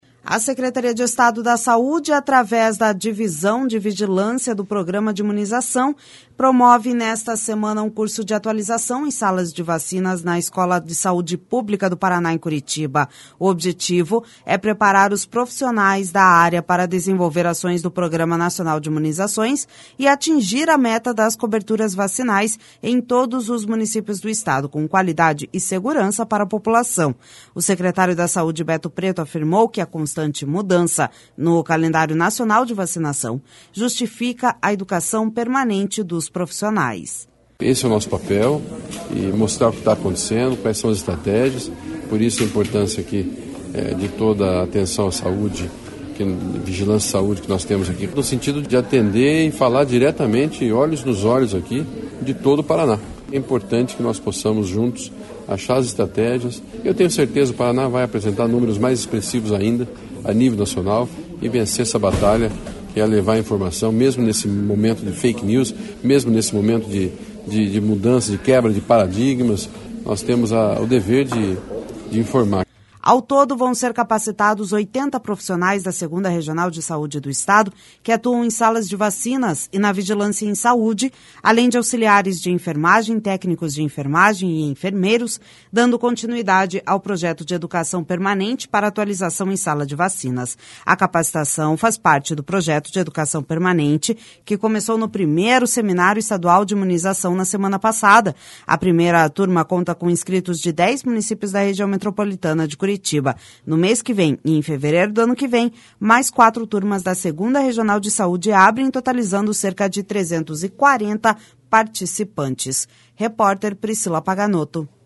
O secretário da Saúde, Beto Preto, afirmou que a constante mudança no calendário nacional de vacinação justifica a educação permanente dos profissionais.// SONORA BETO PRETO//Ao todo, vão ser capacitados 80 profissionais da 2ª Regional de Saúde do Estado, que atuam em salas de vacinas e na vigilância em saúde, além de auxiliares de enfermagem, técnicos de enfermagem e enfermeiros, dando continuidade ao projeto de educação permanente para atualização em sala de vacinas.A capacitação faz parte do projeto de educação permanente que começou no 1º Seminário Estadual de Imunização, na semana passada.